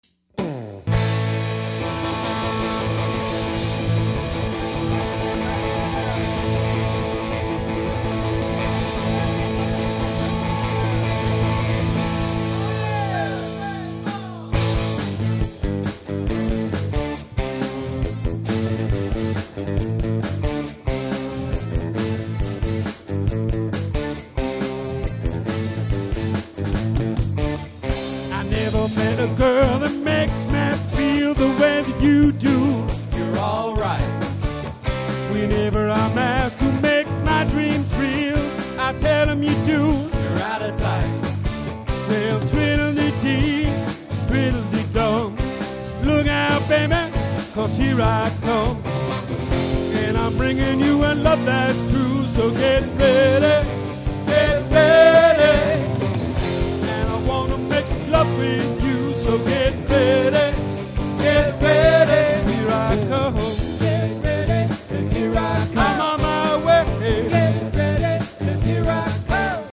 Cover MP3's